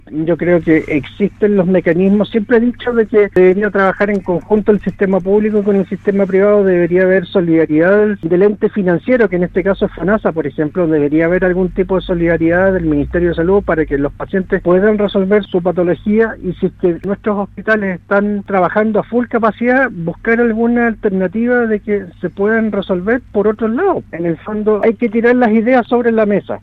expresó en entrevista con Radio Sago su postura de que se realicen alianzas público privadas para avanzar en la concreción de estas cirugías.